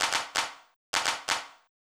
TEC Beat - Mix 16.wav